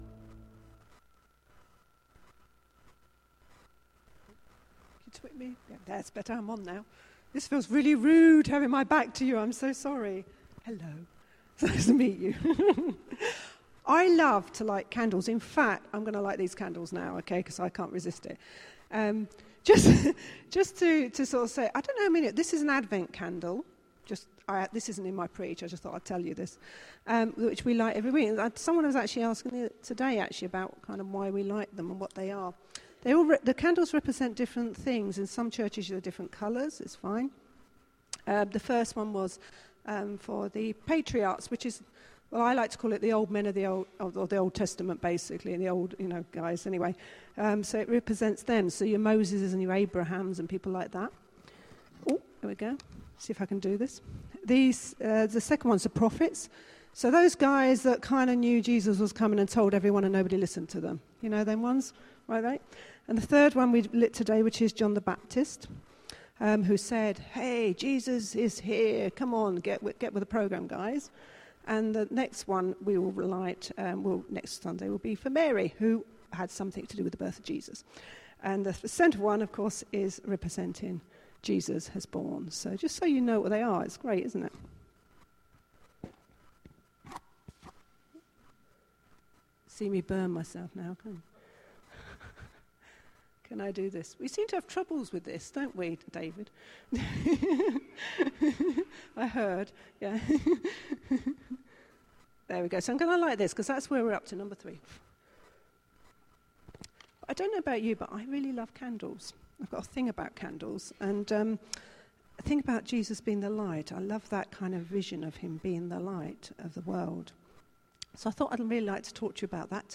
Carol Service – Jesus light of the world – St James New Barnet